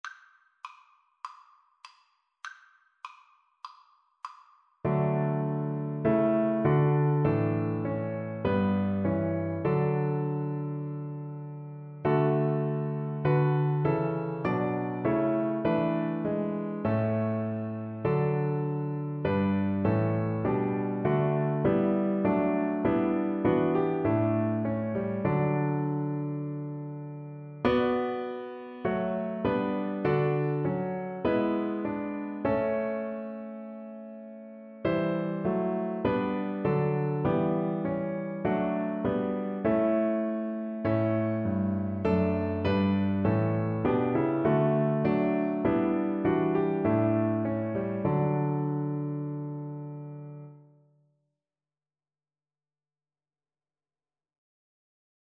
Cello version
Christian
4/4 (View more 4/4 Music)